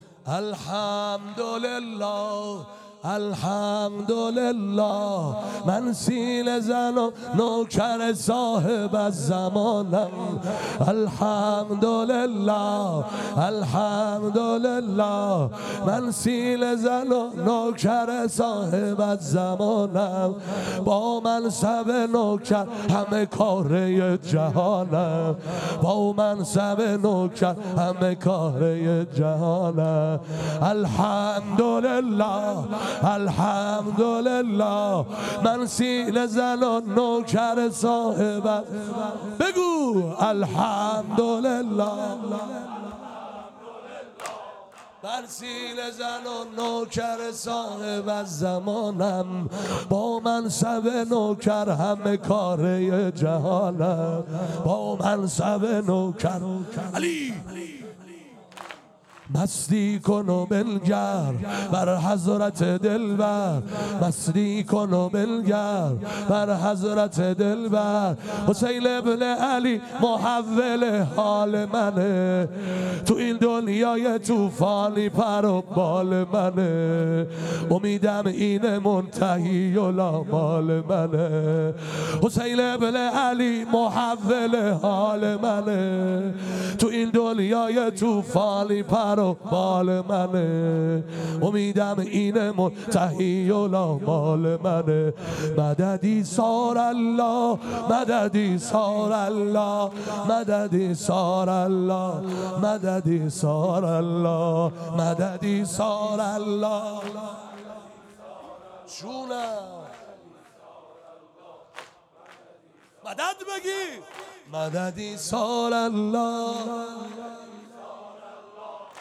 واحد سنگین شب ششم
شب ششم محرم96